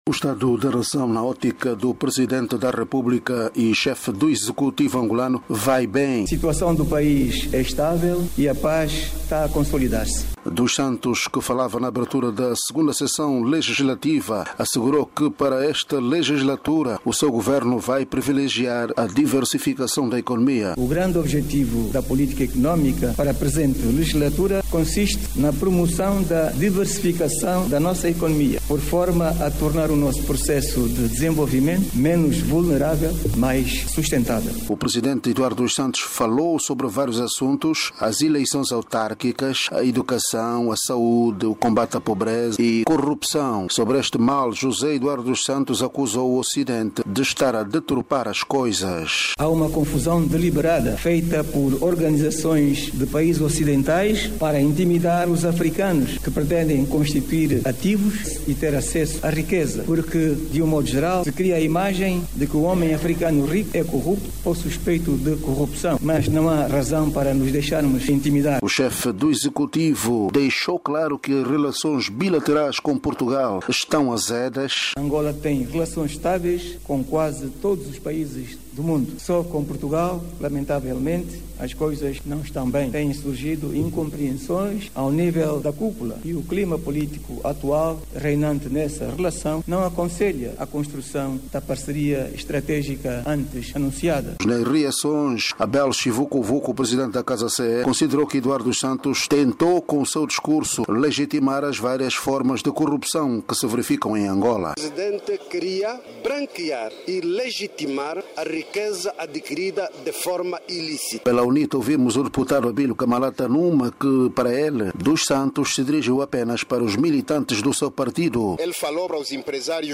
Em discurso ao parlamento presidente diz que situação em Angola é estàvel e promete diversificar economia; diz que acusações de corrupção são falsidades do ocidente